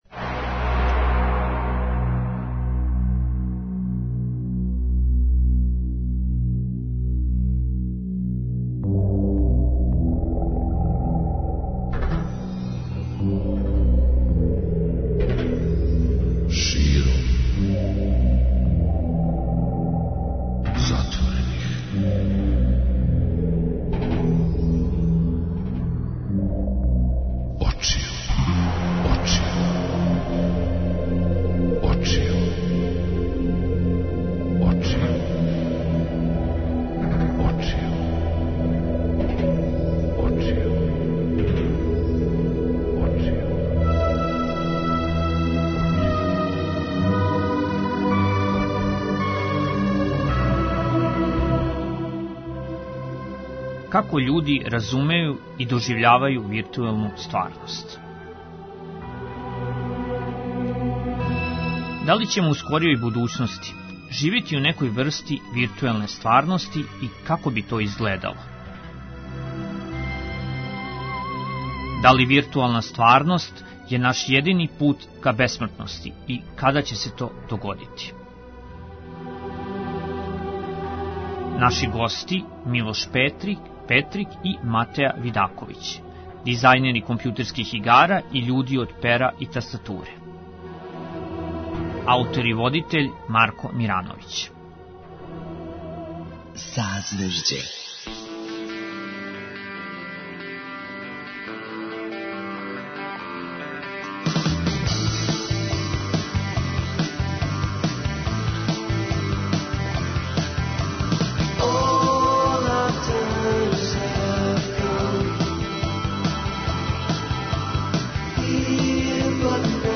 дизајнери компјутерских игара и људи од пера и тастатуре.